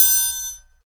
Triangle.wav